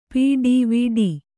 ♪ piḍiviḍi